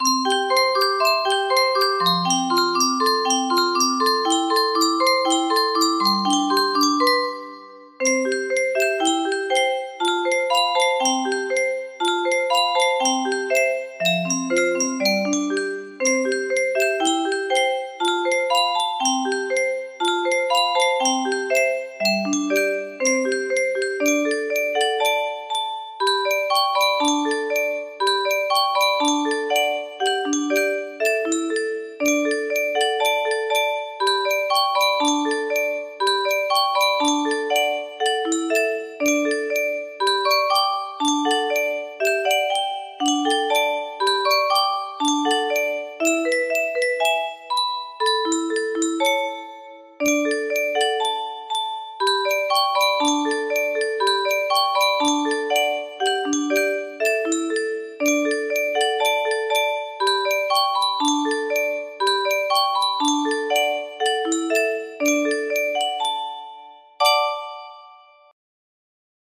Helen music box melody
Grand Illusions 30 (F scale)